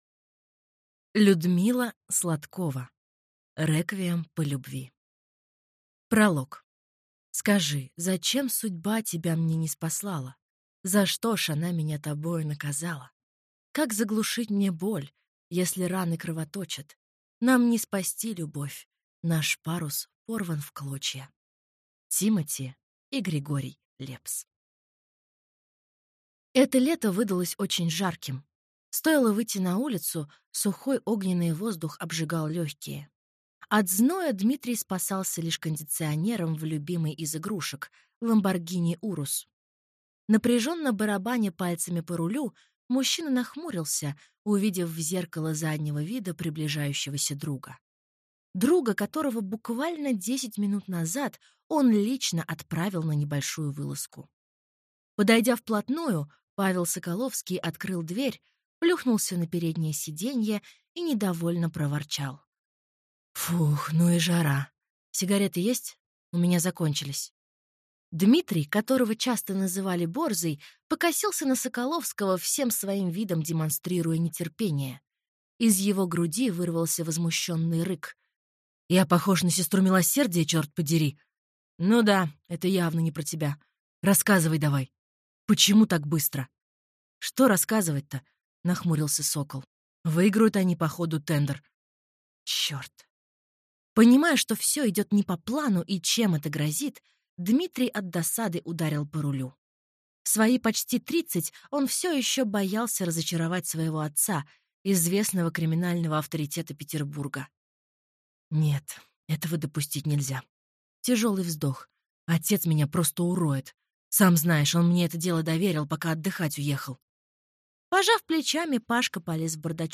Аудиокнига Реквием по любви | Библиотека аудиокниг